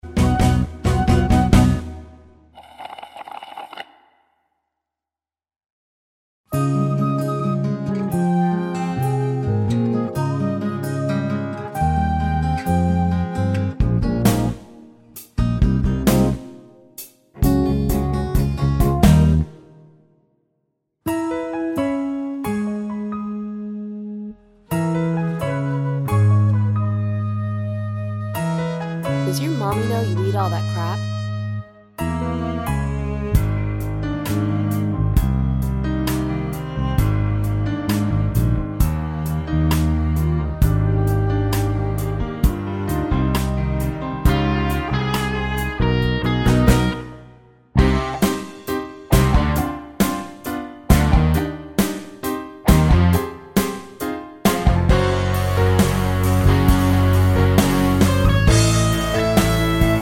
no Backing Vocals Musicals 2:53 Buy £1.50